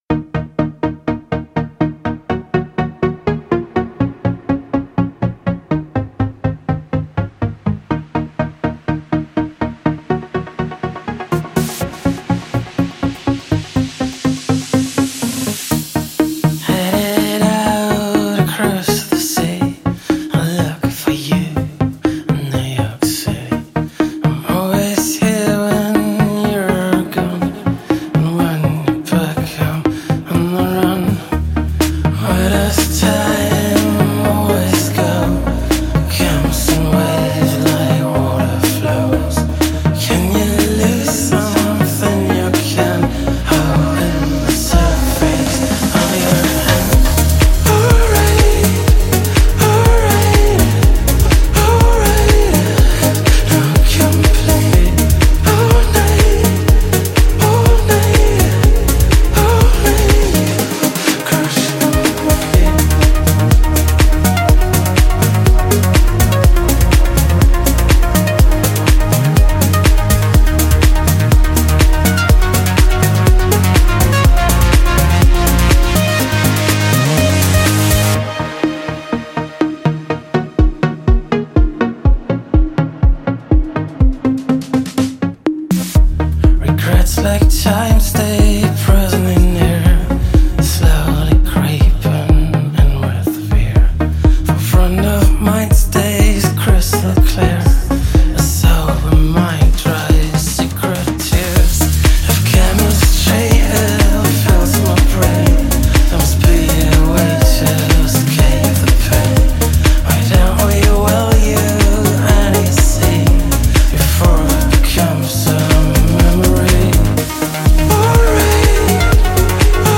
دسته : نیوایج